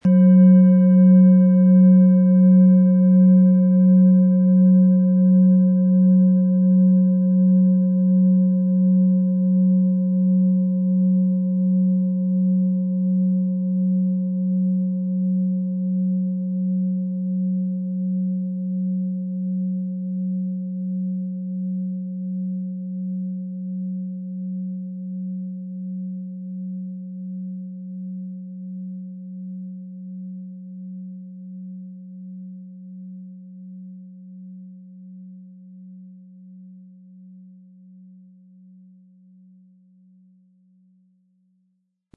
• Tiefster Ton: Mond
• Höchster Ton: Sonne
PlanetentöneDNA & Mond & Sonne (Höchster Ton)
MaterialBronze